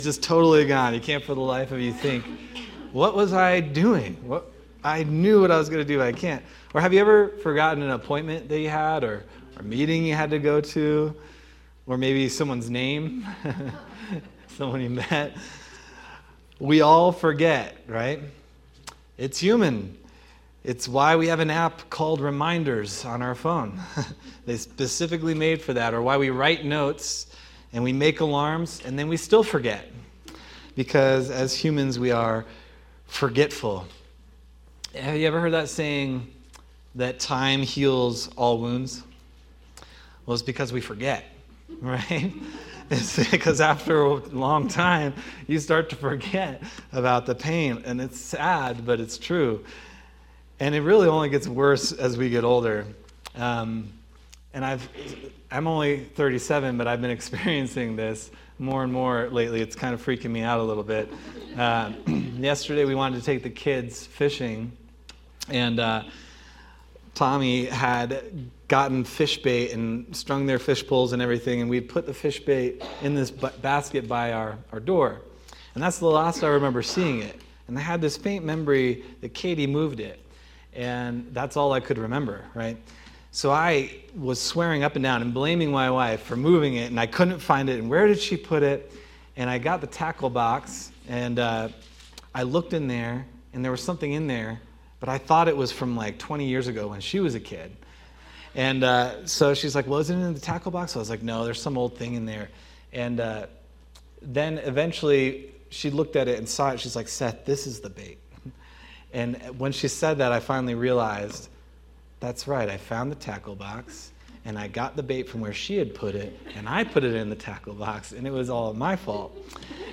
October 5th, 2025 Sermon – Calvary Chapel Nederland